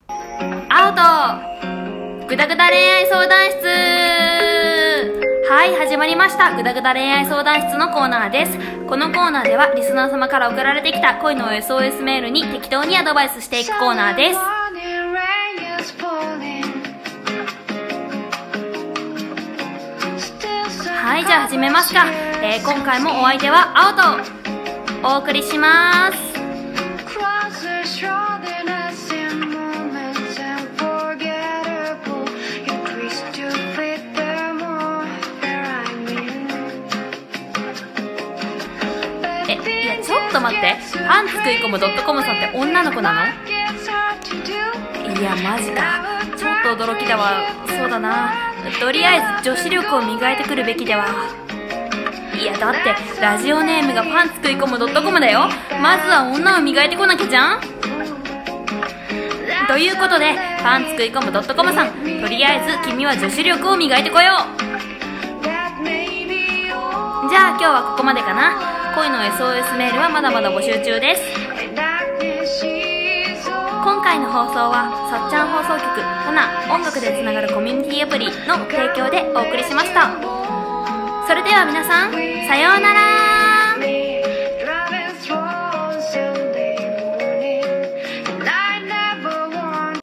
ラジオ風声劇】ぐだぐだ恋愛相談室